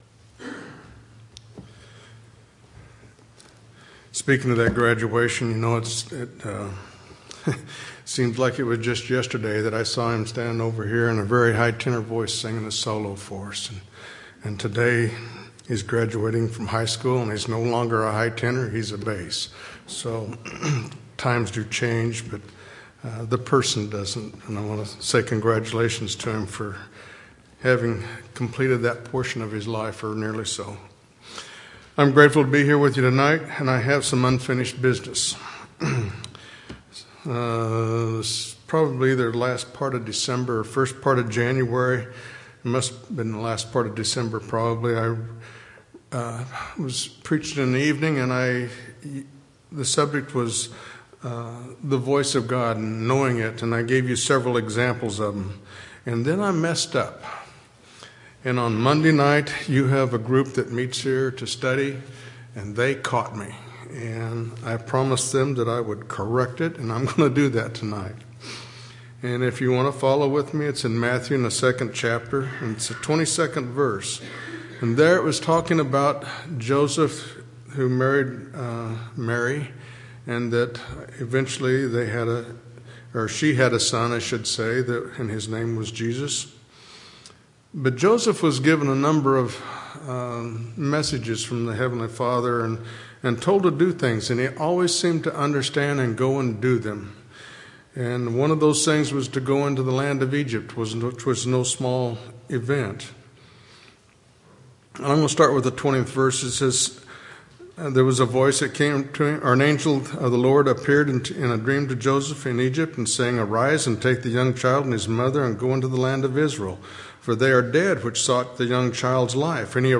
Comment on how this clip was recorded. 4/17/2016 Location: Temple Lot Local Event